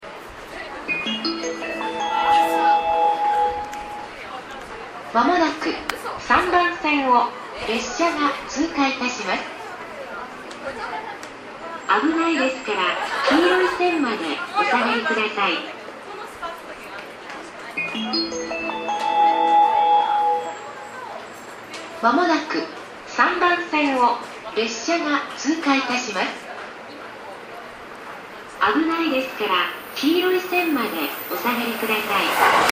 スピーカーはNational横長型となっており音質も比較的よいと思われます。
酒折駅１番線接近放送　　普通　甲府行き接近放送です。